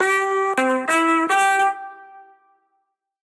X100Horn.ogg